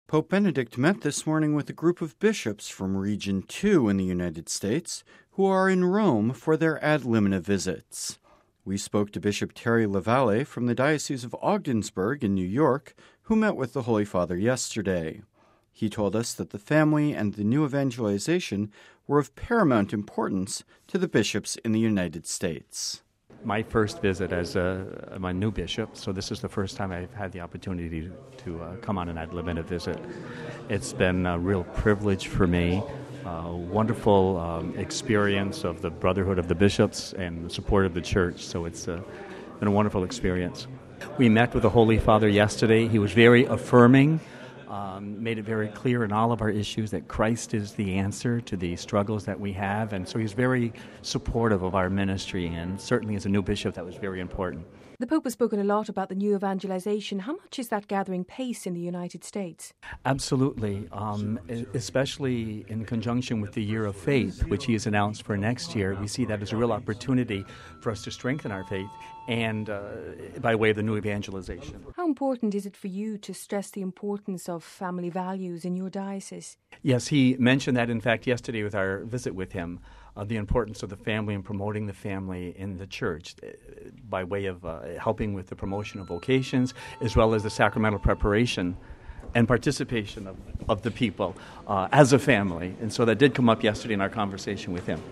We spoke to Bishop Terry La Valley from the diocese of Ogdensburg in New York who met with the Holy Father yesterday.